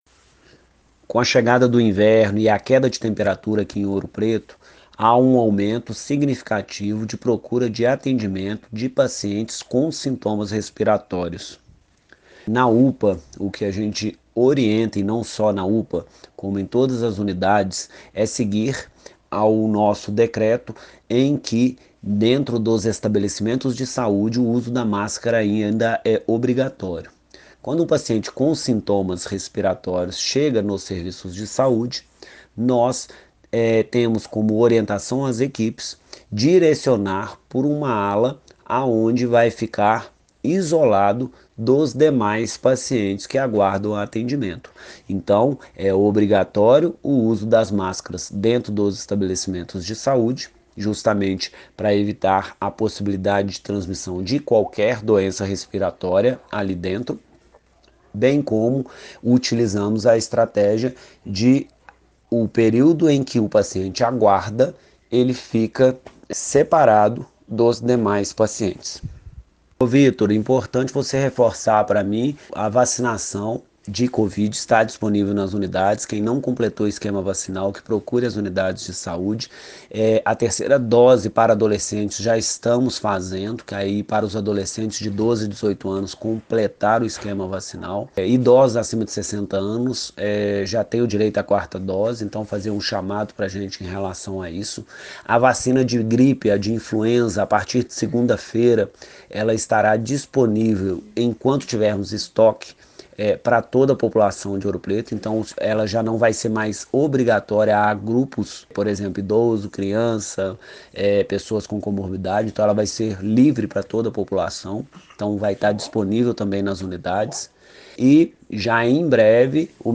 Ouça o áudio exclusivo do secretário Leandro Moreira:
Secretario-de-Saude-alerta.mp3